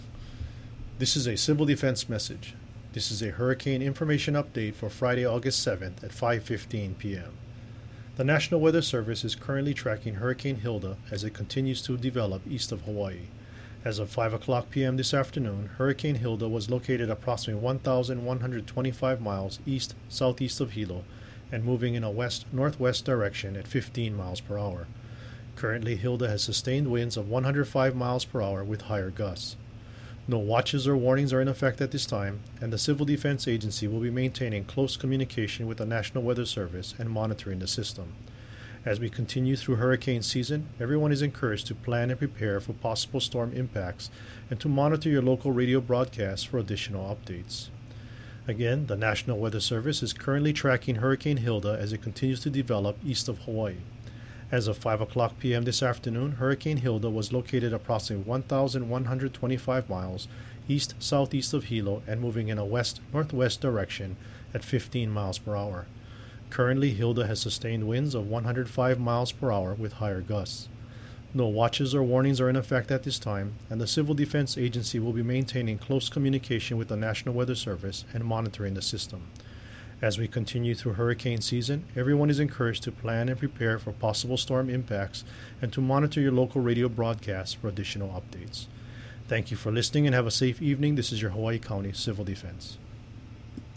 Hawaii County Civil Defense issued its first audio message on Hilda shortly after the 5 pm update from the National Weather Service.